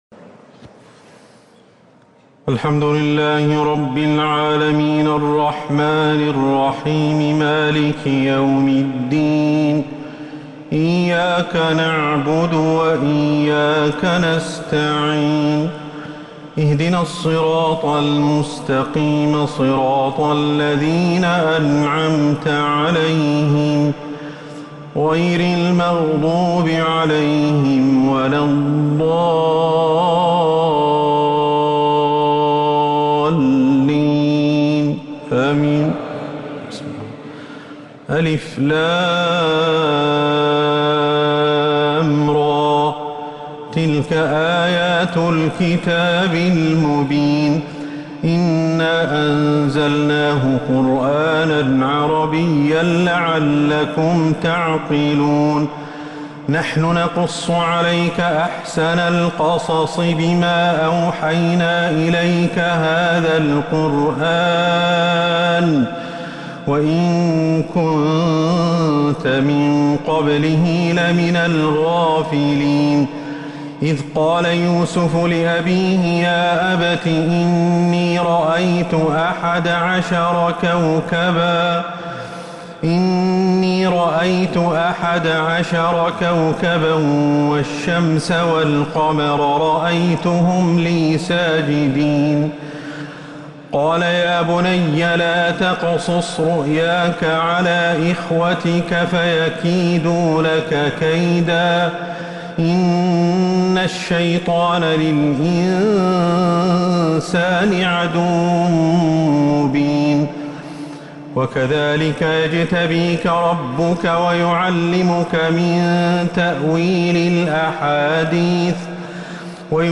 تراويح ليلة 16 رمضان 1447هـ فواتح سورة يوسف {1-57} Taraweeh 16th night Ramadan 1447H Surah Yusuf > تراويح الحرم النبوي عام 1447 🕌 > التراويح - تلاوات الحرمين